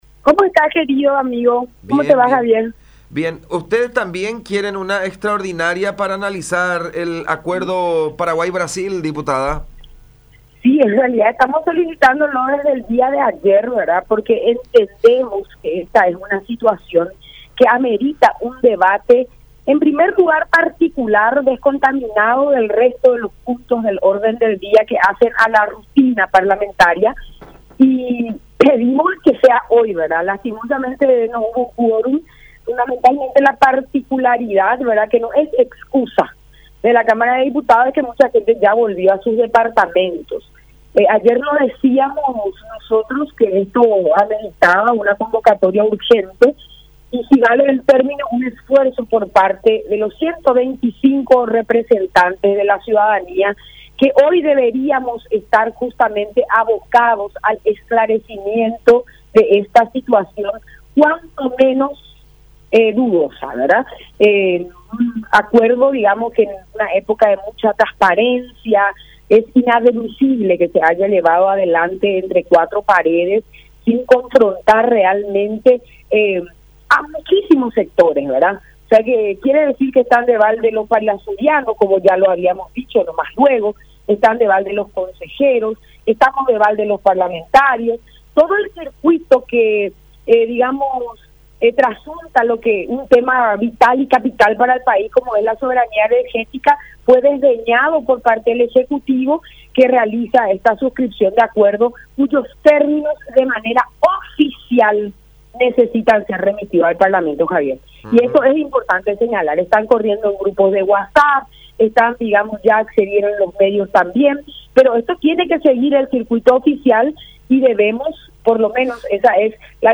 “Es inadmisible que esto se haya llevado a cabo entre cuatro paredes”, repudió la diputada del PEN Kattya González en diálogo con La Unión.
09-Katya-Gonzalez-diputada.mp3